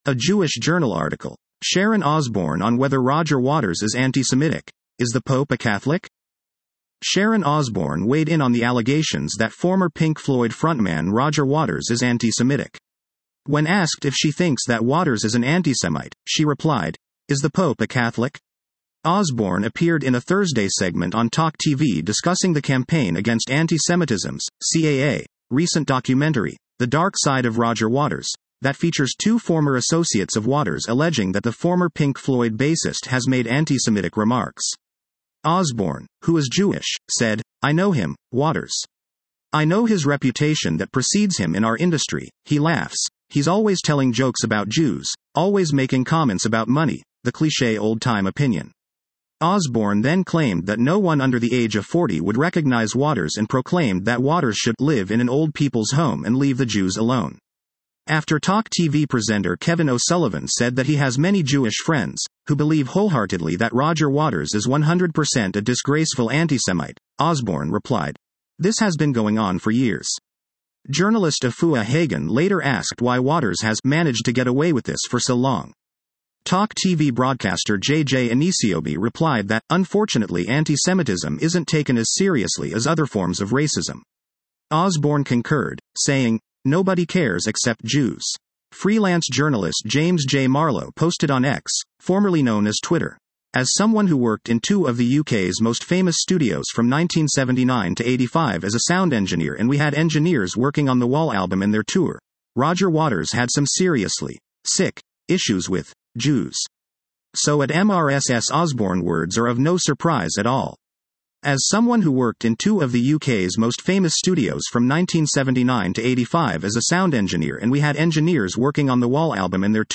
Osbourne appeared in a Thursday segment on TalkTV discussing the Campaign Against Antisemitism’s (CAA) recent documentary “The Dark Side of Roger Waters” that features two former associates of Waters alleging that the former Pink Floyd bassist has made antisemitic remarks.